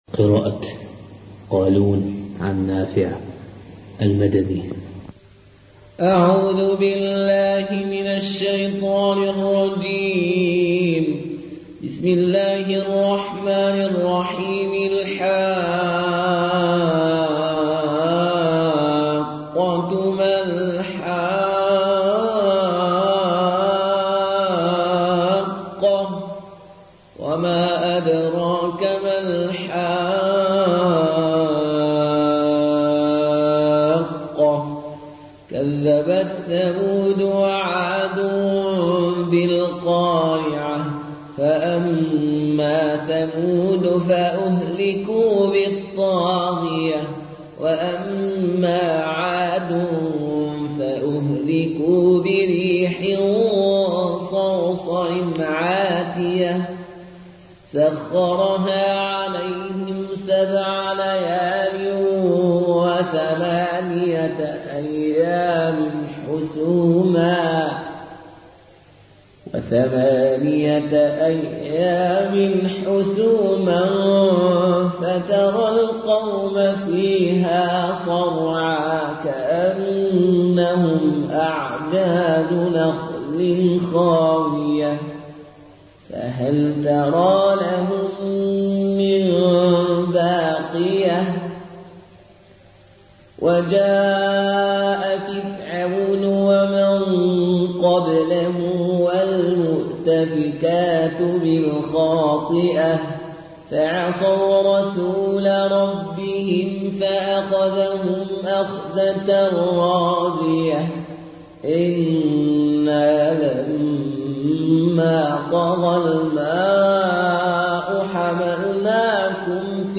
(Riwayat Qaloon)